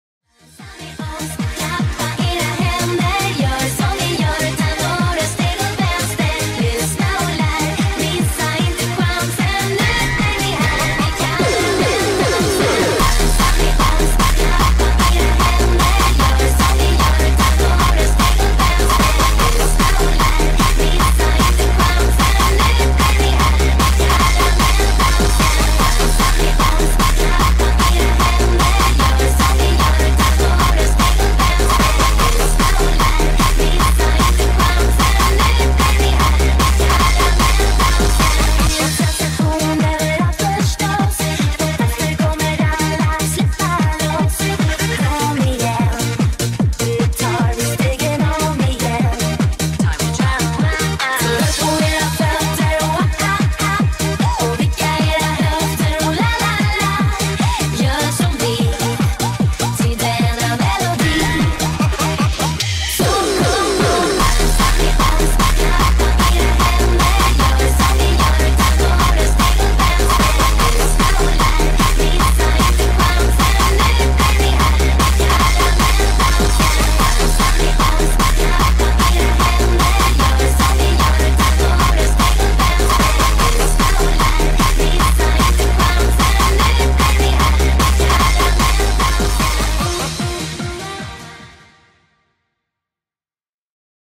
فانک
ماشینی
باشگاهی